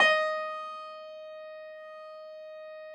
53h-pno15-D3.wav